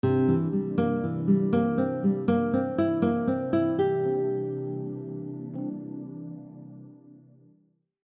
This first example uses an ascending four note pattern using only the C 7 chord tones: C, E, G, and Bb.
Dominant 7 arpeggio example 1
Dominant-7-arpeggio-example-1.mp3